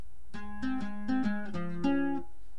para bajosexto!!!!!